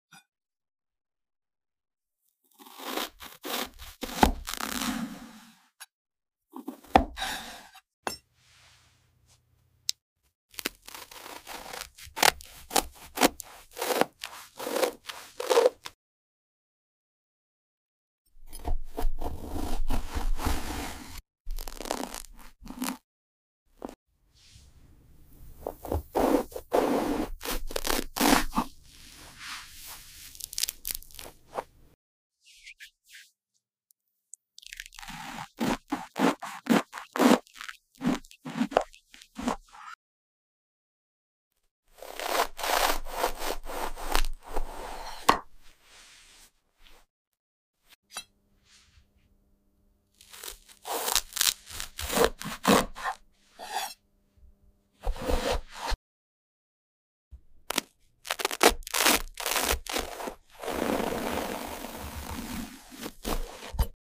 The original soft & squishy fruits are BACK – with even better texture and sound.
No talking. Just pure ASMR bliss 🍓🍊🍇 Turn up your volume 🎧 and enjoy Part 4 of this relaxing series.